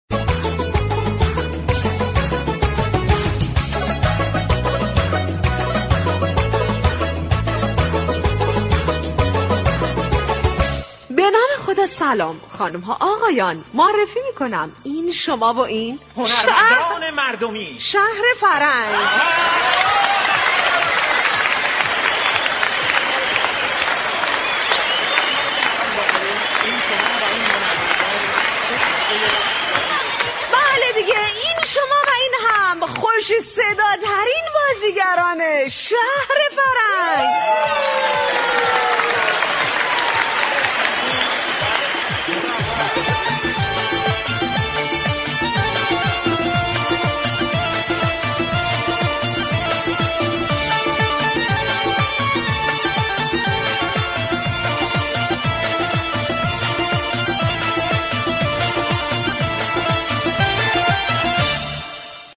به گزارش روابط عمومی رادیو صبا ، «شهر فرنگ» در قالب جنگ مفرح با نمایش های طنز و شاد هر روز از رادیو صبا پخش می شود .
این برنامه با پخش موسیقی و ترانه های نشاط آفرین به همراه آیتمهای نمایشی كوتاه طنز و لطیفه های شیرین با بیان طنز به نقد مسایل سیاسی ، اقتصادی، ورزشی ، اجتماعی در قالب نمایش های طنز و لطیفه می پردازد.